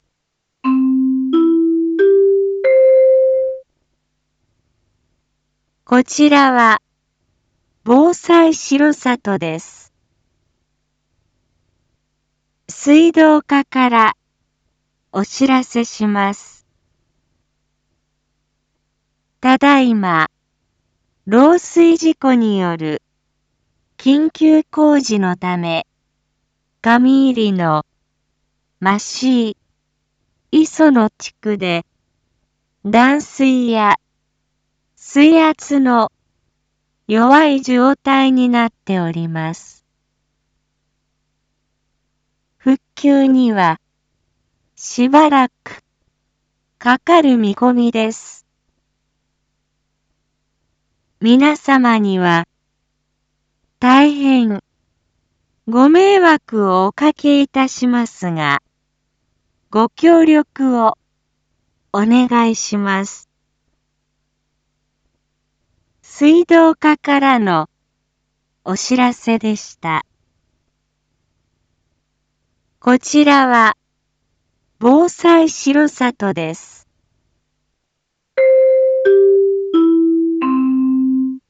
Back Home 一般放送情報 音声放送 再生 一般放送情報 登録日時：2023-05-19 09:11:23 タイトル：漏水事故による断水について（上入野・増井・磯野地区） インフォメーション：こちらは、防災しろさとです。